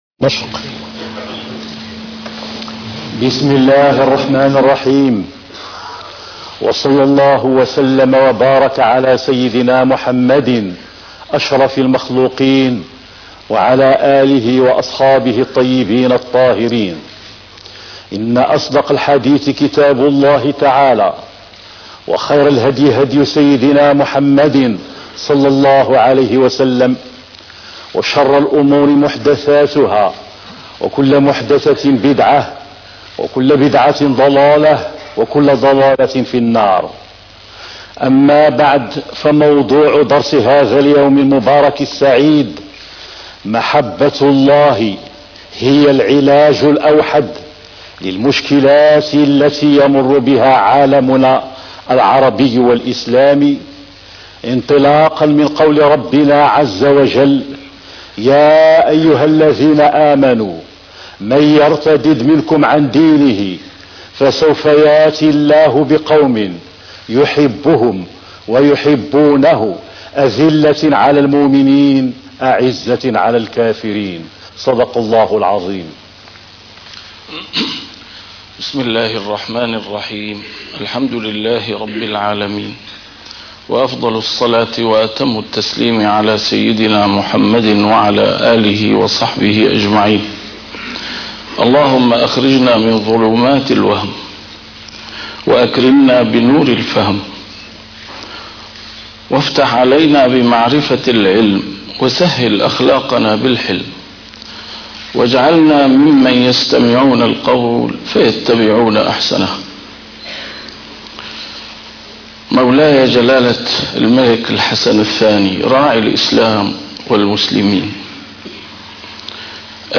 محاضرات متفرقة في مناسبات مختلفة - A MARTYR SCHOLAR: IMAM MUHAMMAD SAEED RAMADAN AL-BOUTI - الدروس العلمية - الدروس الحسنية : محبة الله هي العلاج الأوحد لمشكلات عالمنا العربي والإسلامي